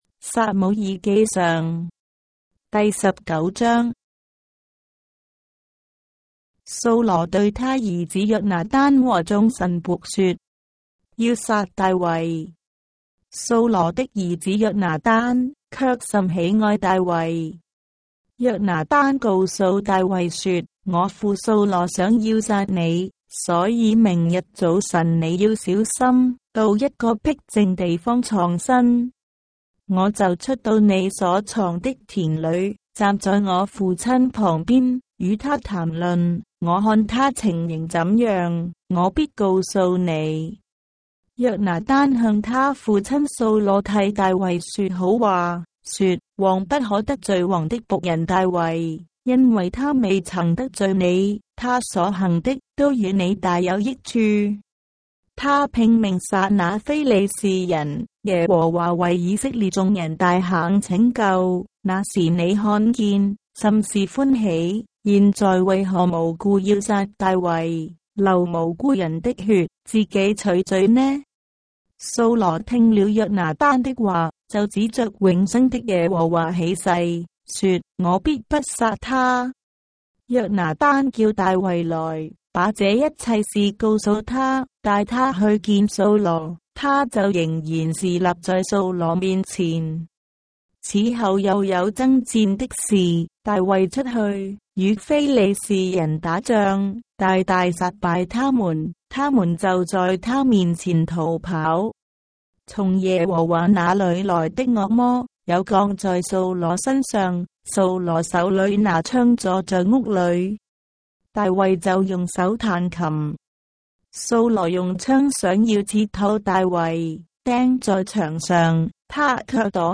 章的聖經在中國的語言，音頻旁白- 1 Samuel, chapter 19 of the Holy Bible in Traditional Chinese